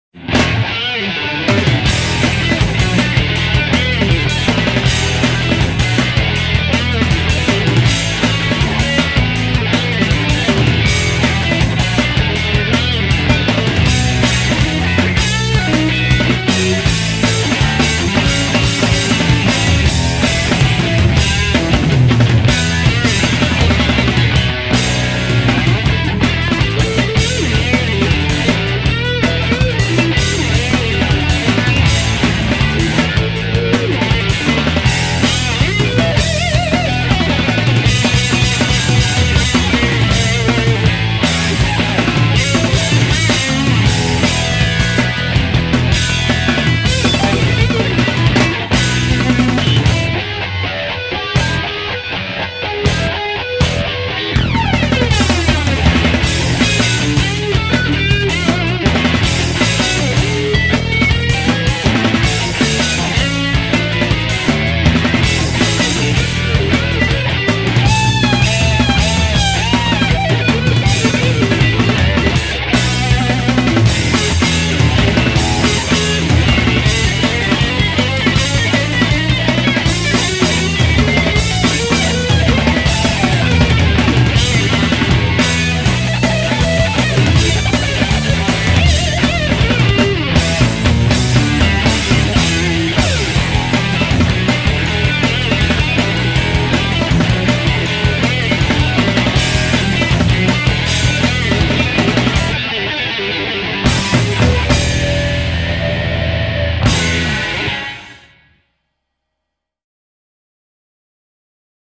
퓨젼곡